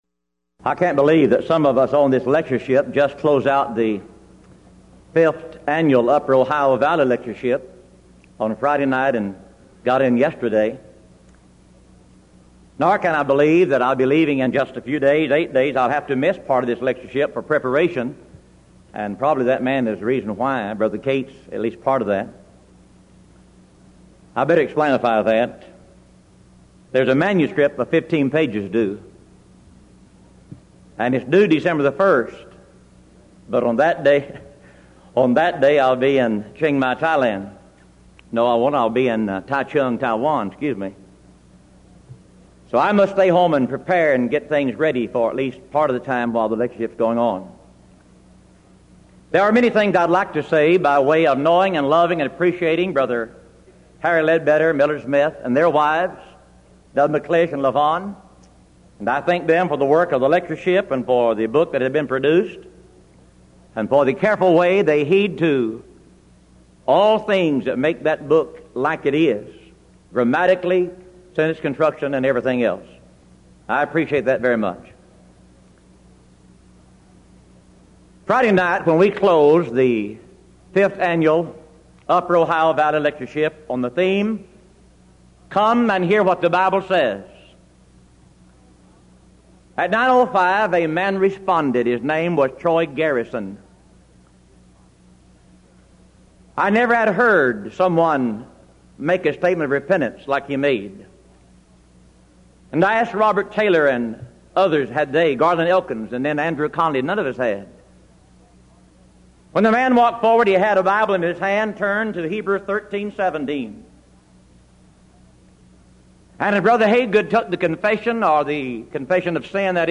Denton Lectures